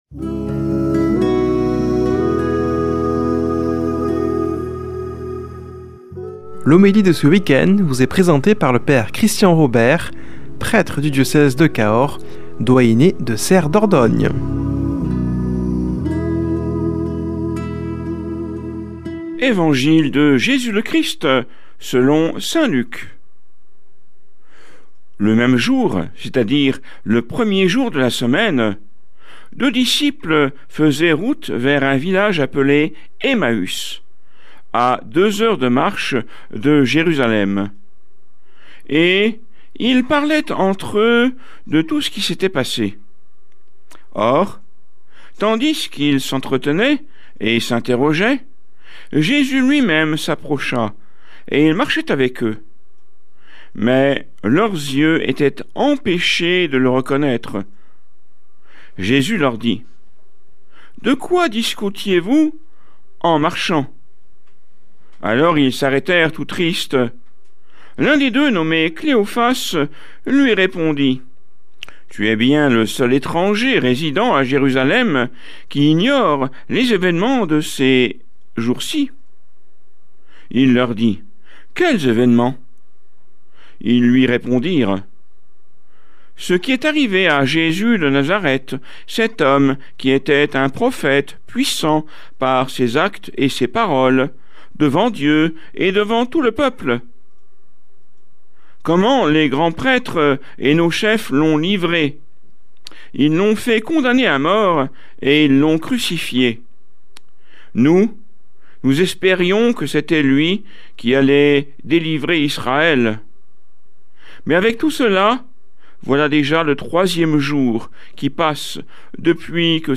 Homélie du 18 avr.